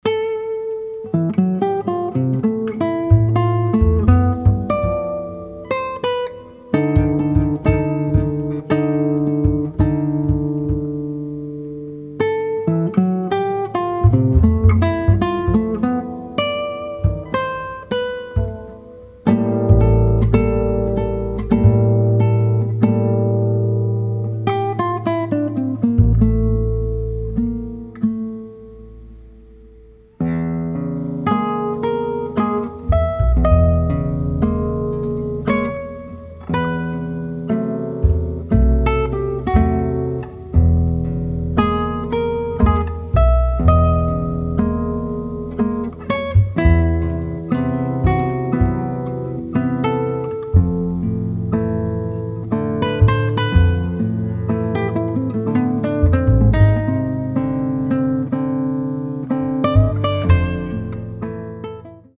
acoustic guitar
acoustic bass